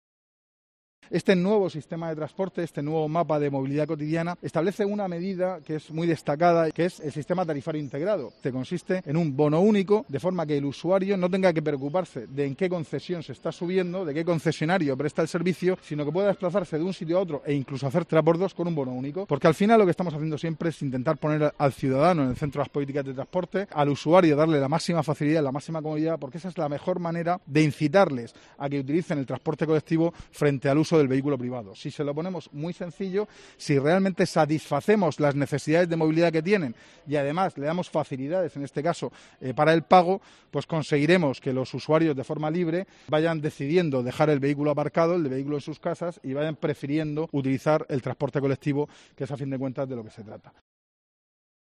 José Ramón Díez de Revenga, consejero de Fomento